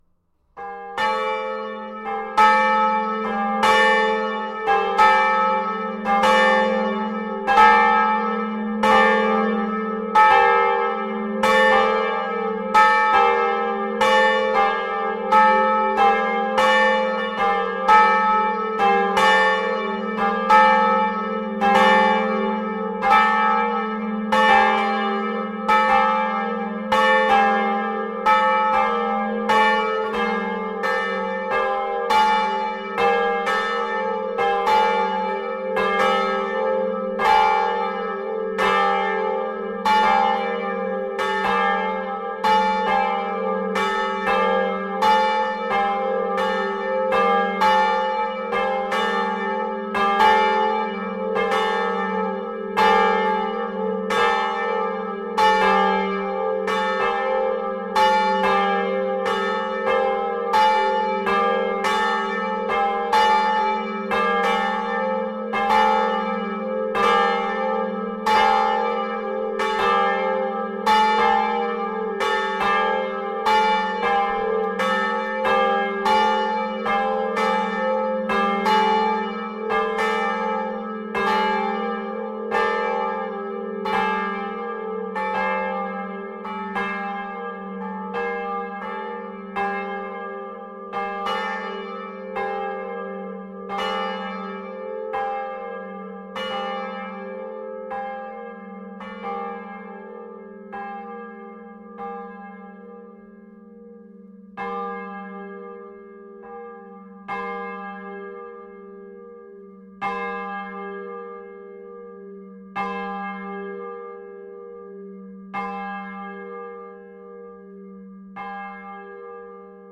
Trois cloches sont abritées dans un beffroi en bois spacieux.
Note et harmoniques : Hum : 175 Hertz. Prime : 351 Hertz. Tierce : 417 Hertz. Nominal : 700 Hertz. Superquint : 1051 Hertz. Oct nom : 1453 Hertz. Nombre d'harmoniques détectées : 15.
Note et harmoniques : Hum : 193 Hertz. Prime : 396 Hertz. Tierce : 474 Hertz. Quint : 609 Hertz. Nominal : 801 Hertz. Superquint : 1193 Hertz. Oct nom : 1638 Hertz. Nombre d'harmoniques détectées : 11.
Note et harmoniques : Hum : 116 Hertz. Prime : 439 Hertz. Tierce : 522 Hertz. Nominal : 863 Hertz. Superquint : 1291 Hertz. Oct nom : 1783 Hertz. Nombre d'harmoniques détectées : 10.
Vous pouvez écouter ci-dessous la volée (2,08mn) :
floreffe-volee.mp3